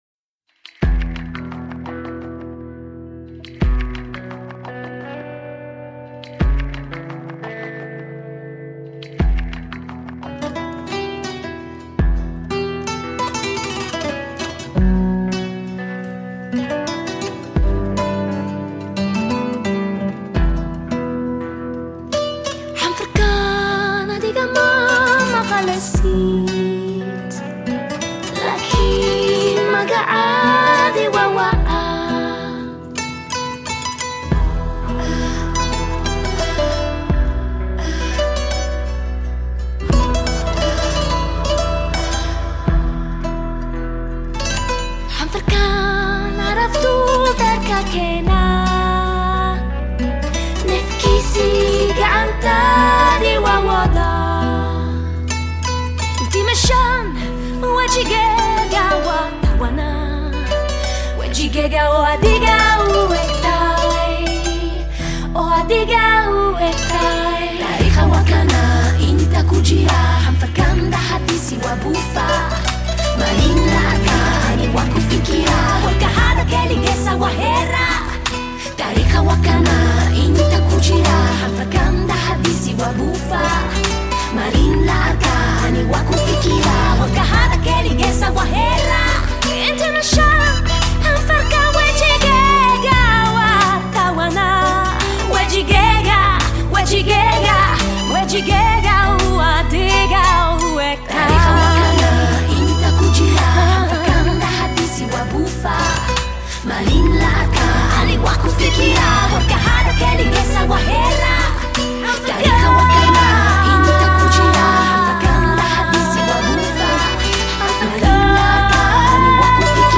Жанр: Ethnic, World Music, Vocal